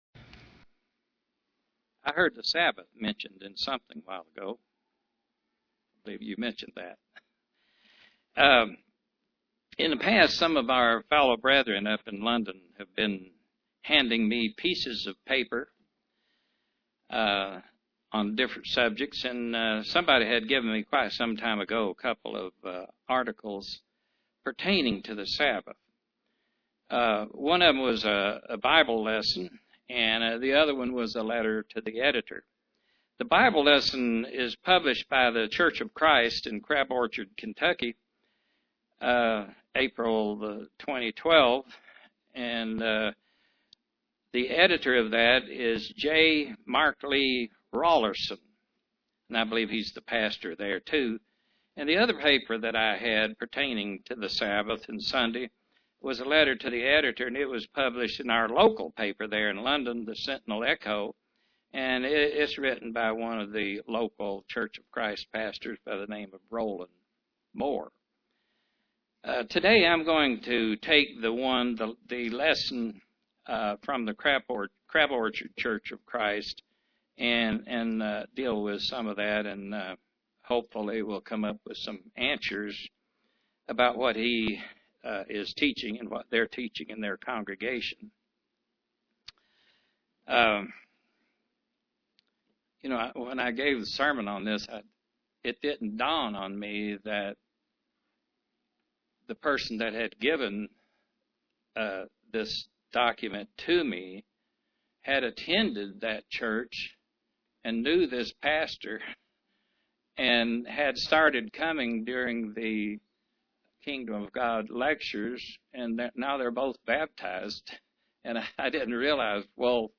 Print Misconceptions about the Sabbath day UCG Sermon Studying the bible?